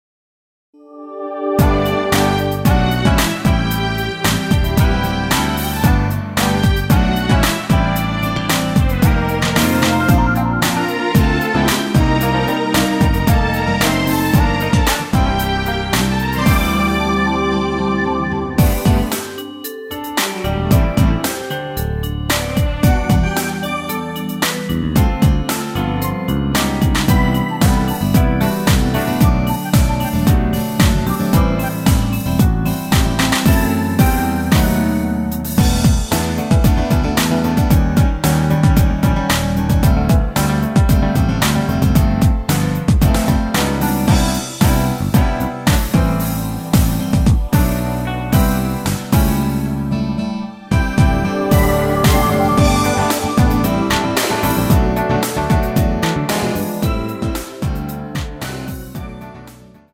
1절 삭제한 MR 입니다.
원곡의 보컬 목소리를 MR에 약하게 넣어서 제작한 MR이며